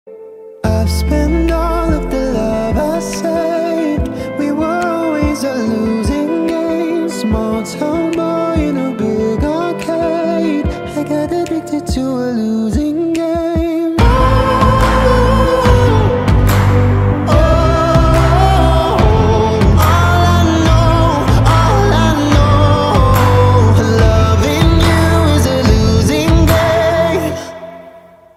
heartfelt ballad